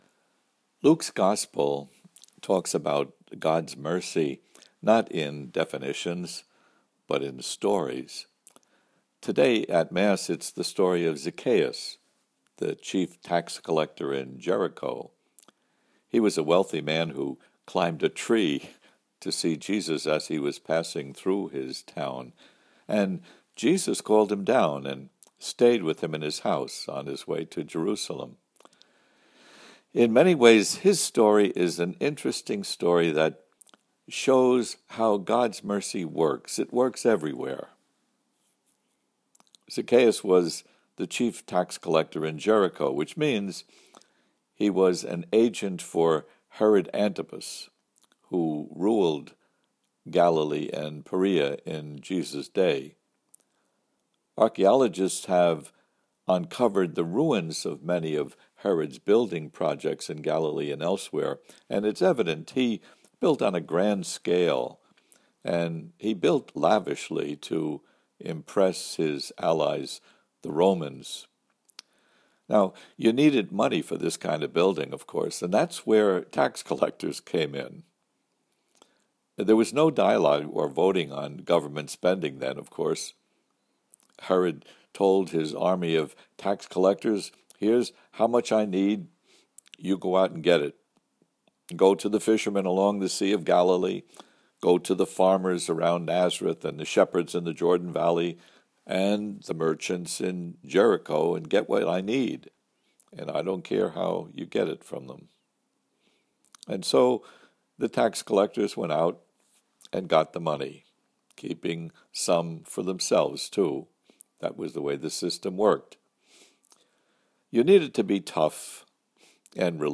Audio homily here: